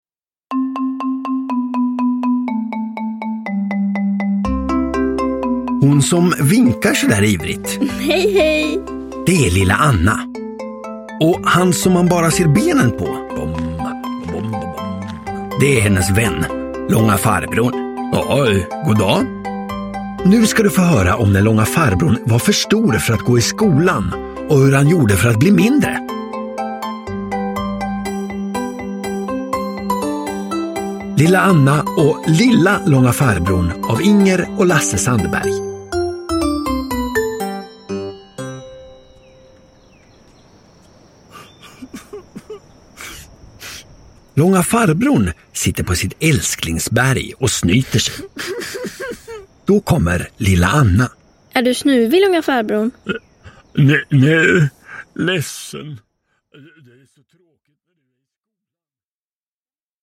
Lilla Anna och lilla Långa Farbrorn – Ljudbok – Laddas ner